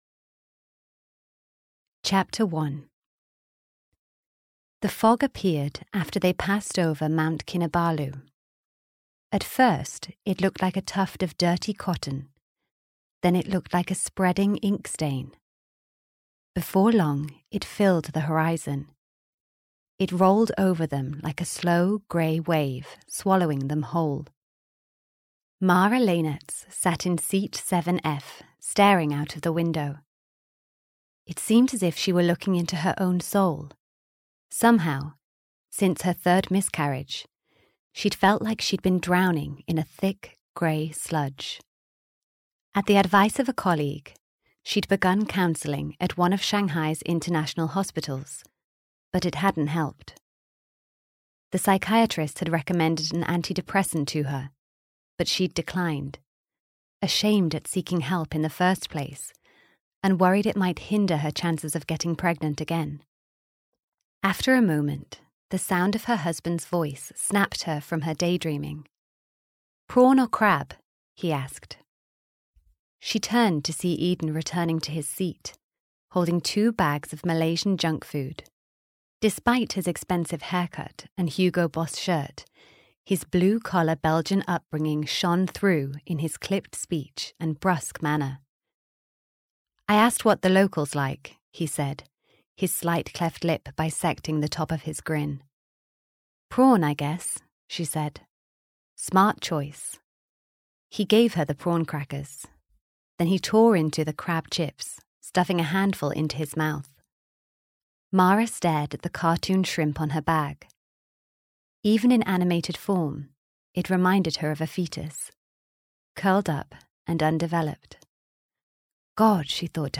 The Depths (EN) audiokniha
Ukázka z knihy